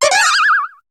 Cri de Mime Jr. dans Pokémon HOME.